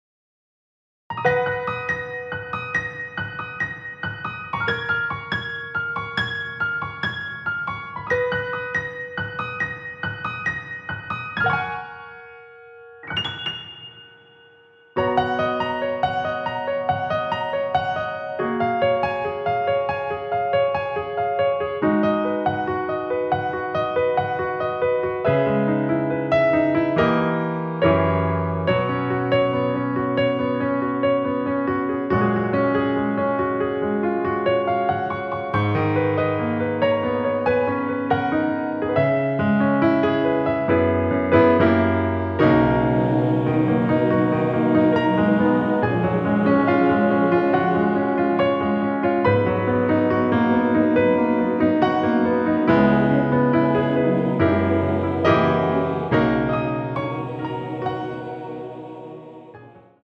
원키에서(-2)내린 MR입니다.
F#
앞부분30초, 뒷부분30초씩 편집해서 올려 드리고 있습니다.